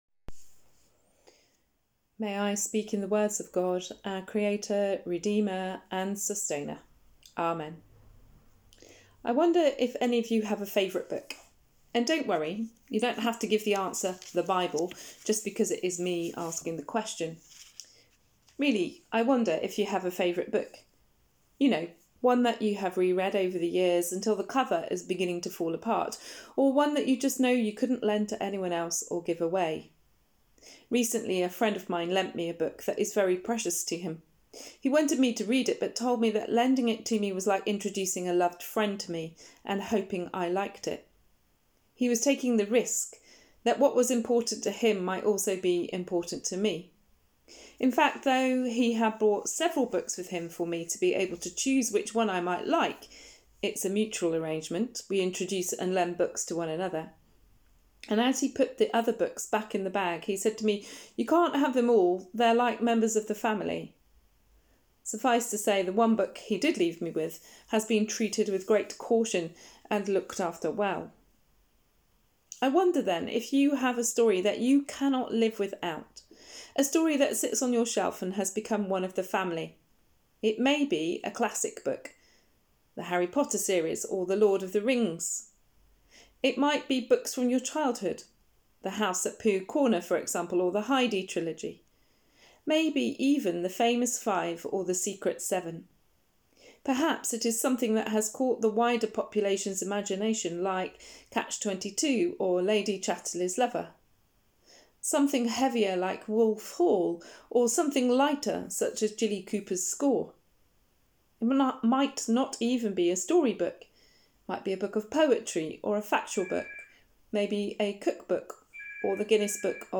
Sermon: Our Story and God’s Story | St Paul + St Stephen Gloucester